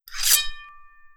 Sword5.wav